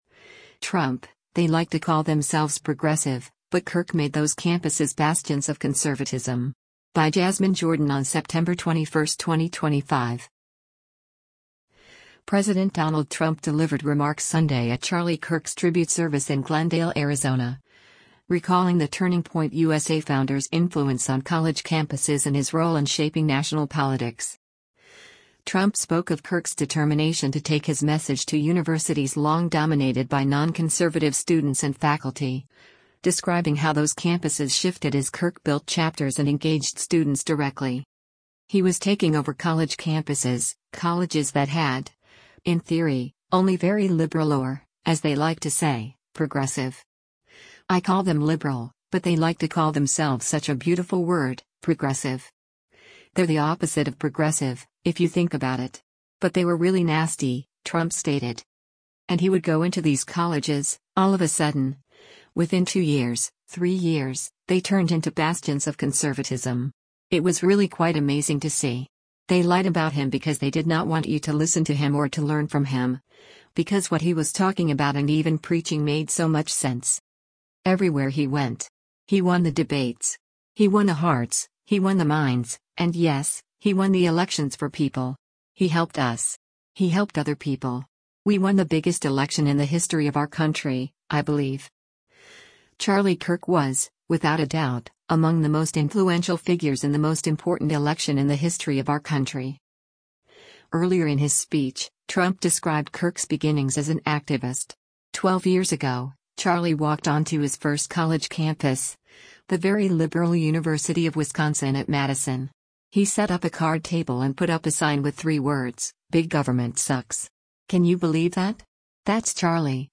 GLENDALE, ARIZONA - SEPTEMBER 21: U.S. President Donald Trump speaks during the memorial s
President Donald Trump delivered remarks Sunday at Charlie Kirk’s tribute service in Glendale, Arizona, recalling the Turning Point USA founder’s influence on college campuses and his role in shaping national politics. Trump spoke of Kirk’s determination to take his message to universities long dominated by non-conservative students and faculty, describing how those campuses shifted as Kirk built chapters and engaged students directly.
Trump’s remarks came during the “Building a Legacy: Remembering Charlie Kirk” service at State Farm Stadium, where he was greeted with cheers and chants of “USA” from attendees as he was shown on the jumbotron.